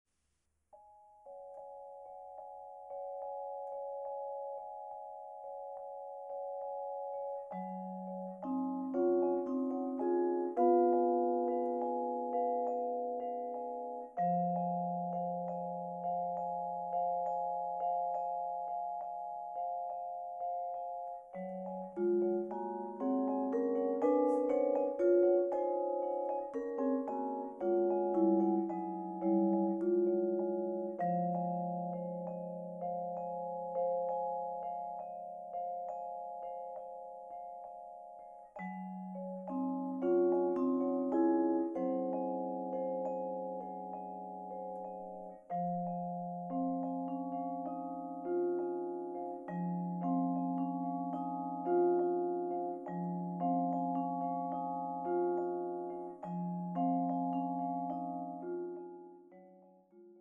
Vibraphone
Challenging collection of solos for vibraphone,
combining contemporary performance techniques
with rich harmonic and melodic content.